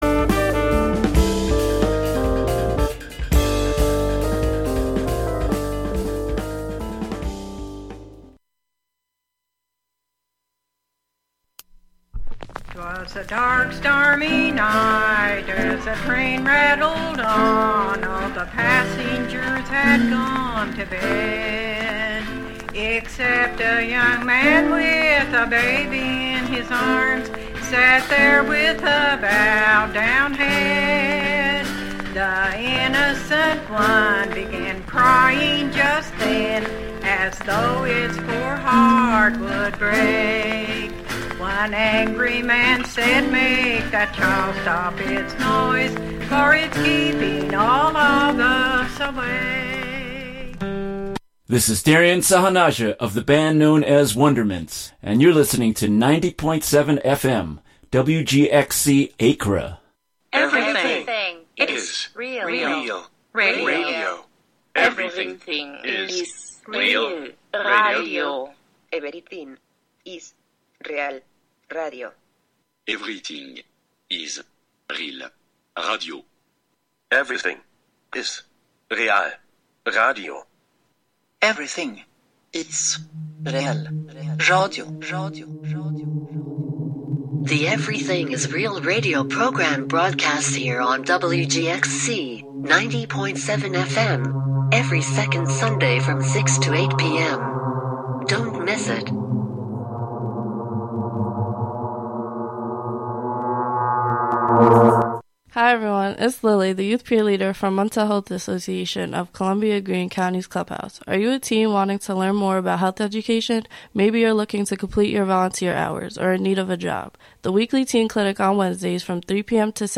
Classic soul music